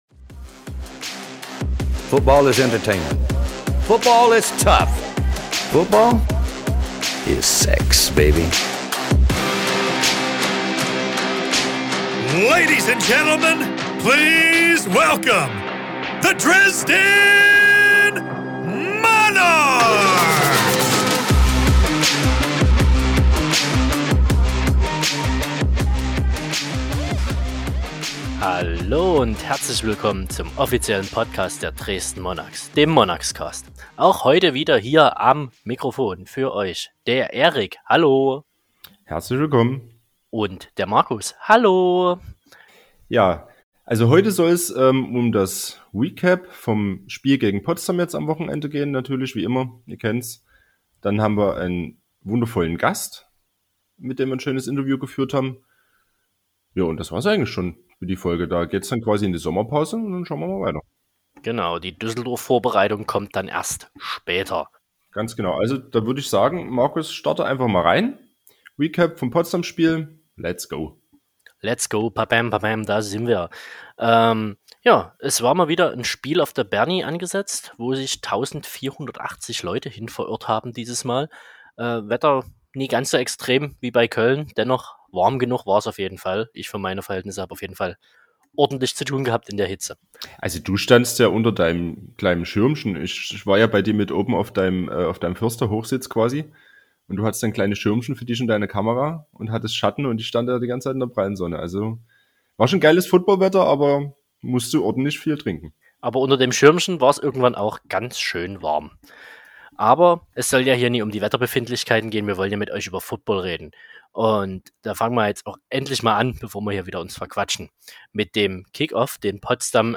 Ihr dürft gespannt sein, wen wir uns diesmal zum Interview heranholen.